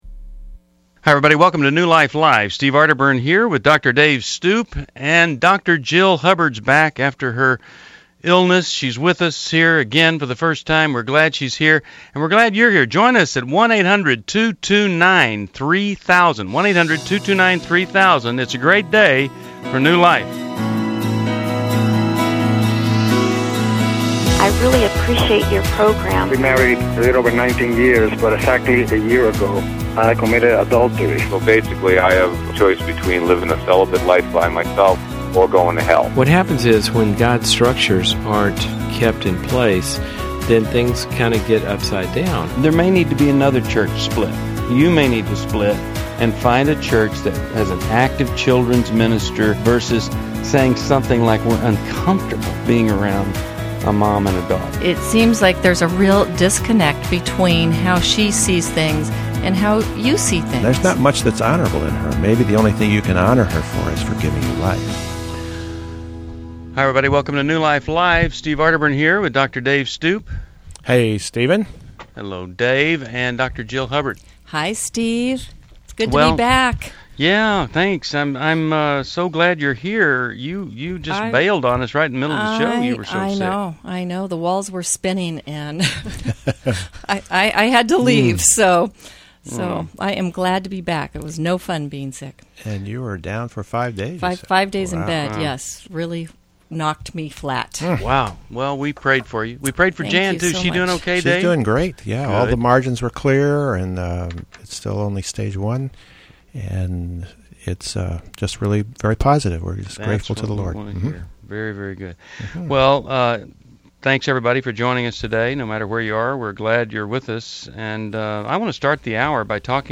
New Life Live: September 20, 2011 - Explore grief, anxiety, and addiction as our hosts tackle tough questions about relationships, anger, and healing.
Caller Questions: 1. Can an itchy scalp be caused by anxiety?